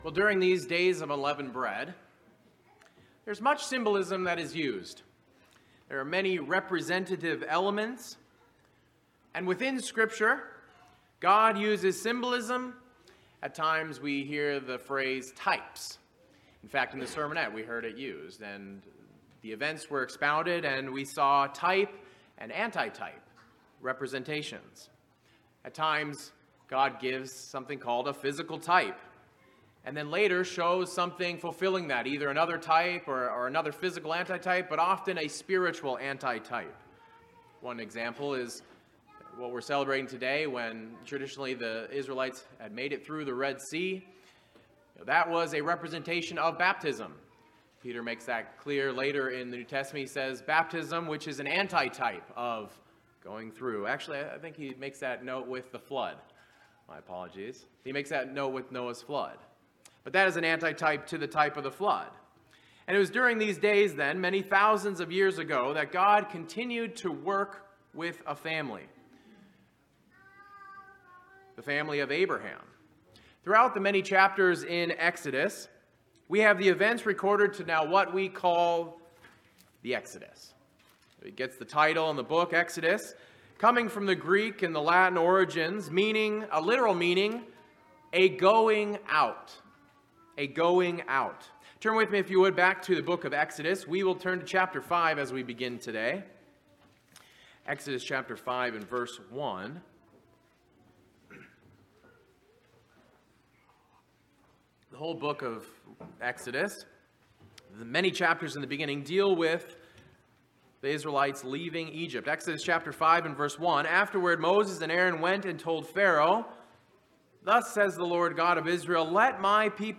Sermons
Given in Bangor, ME Saratoga Springs, NY Southern New Hampshire Worcester, MA